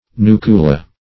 Search Result for " nucula" : The Collaborative International Dictionary of English v.0.48: Nucula \Nu"cu*la\, n. [L., little nut, dim. of nux, nucis, a nut.]